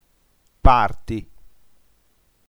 parti nf pàr.ti - ['parti] ◊